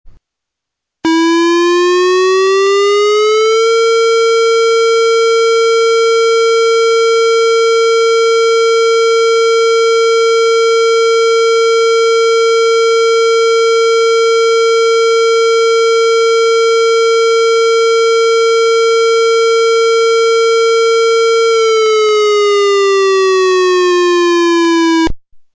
The system is designed to provide audible public warning signals (tone) and voice messages within a range of approximately one mile (5,200 ft.) from the center of Telford Borough in all directions.
1. ‘Alert’ tone (Tornado Warning)
alert.mp3